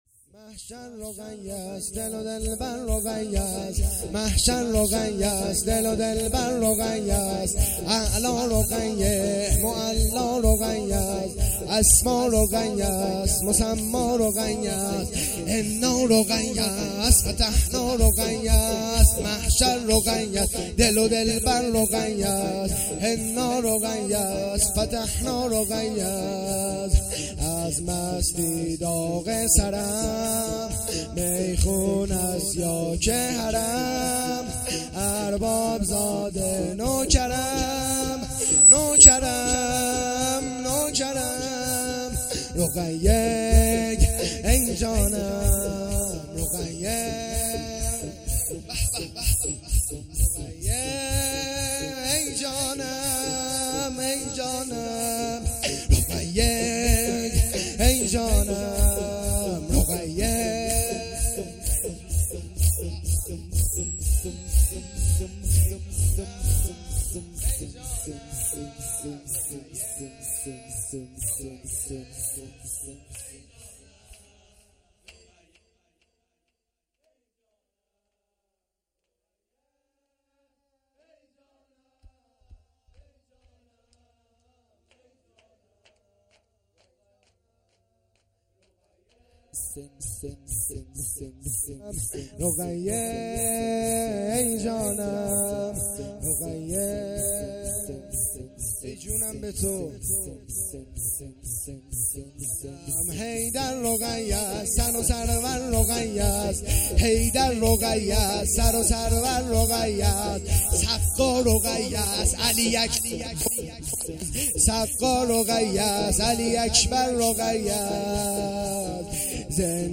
شور
شام شهادت حضرت رقیه(س)۱۴۰۰